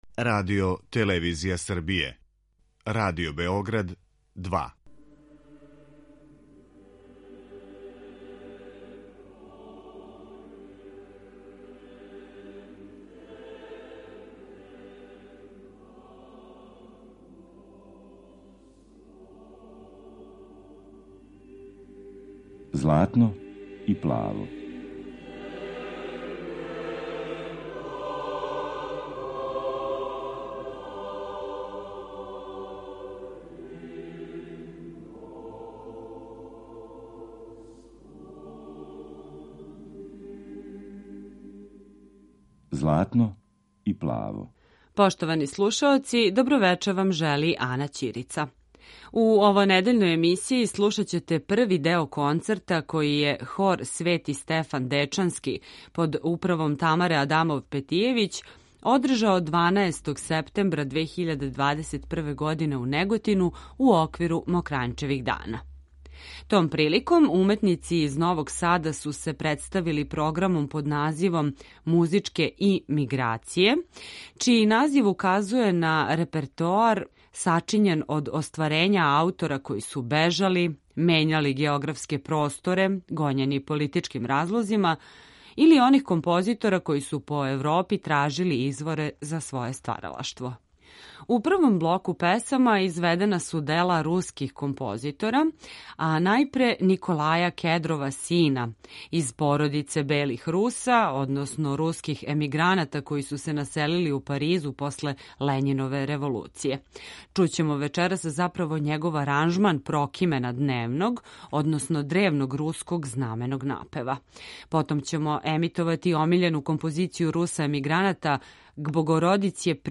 Концерт Хора „Свети Стефан Дечански” у Неготину
Емисија посвећена православној духовној музици.